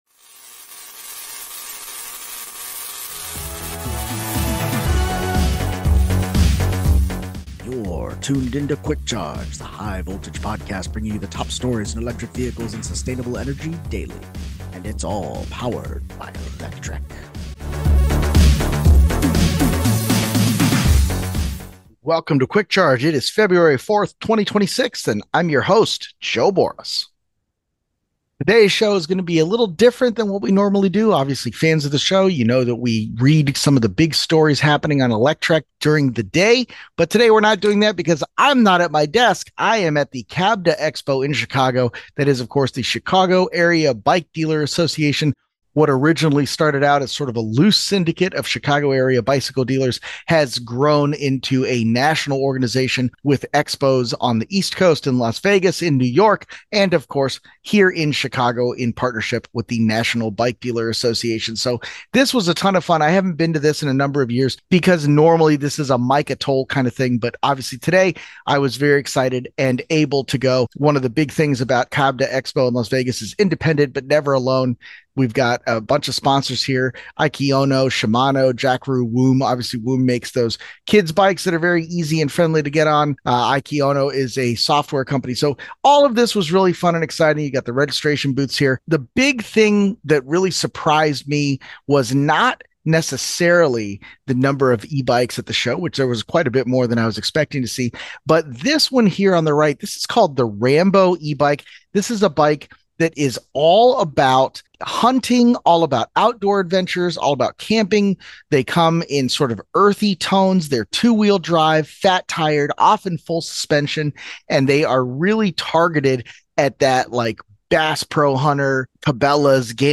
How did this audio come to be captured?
On today's show-stopping episode of Quick Charge, we're on location at the midwest's largest independent bicycle dealer show checking out some of the highlights from Himiway, Radio Flyer, Rambo, and a whole lot more!